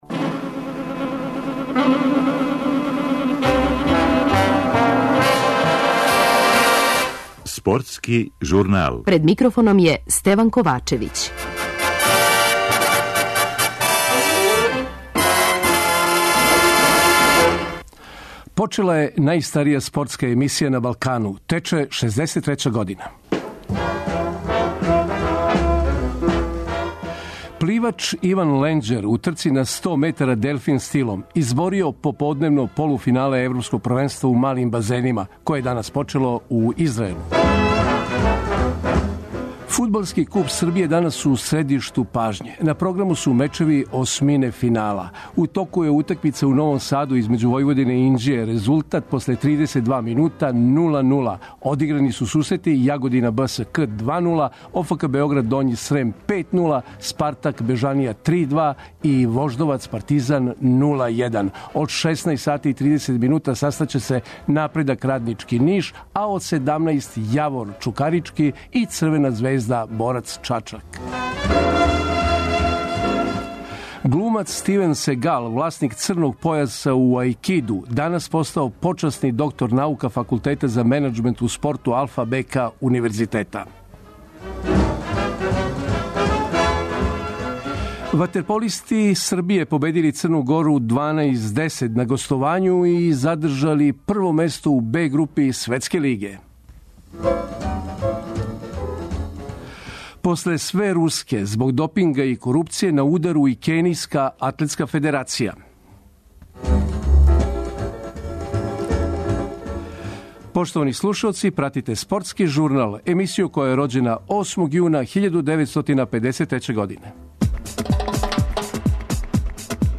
Следе директна укључења, извештаји са већ одиграних утакмица, најава каснопоподневних.